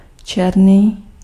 Ääntäminen
Ääntäminen : IPA: [zʋaɾt] Tuntematon aksentti: IPA: /zʋɑrt/ Haettu sana löytyi näillä lähdekielillä: hollanti Käännös Ääninäyte 1. černý 2. čerň {f} Suku: m .